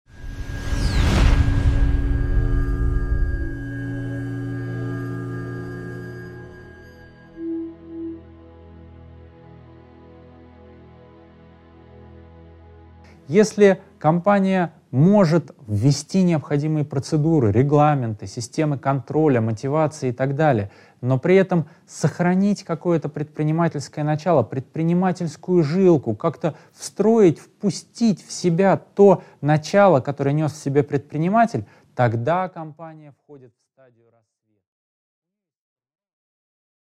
Аудиокнига 5.7. Модель жизненных циклов Адизеса, продолжение | Библиотека аудиокниг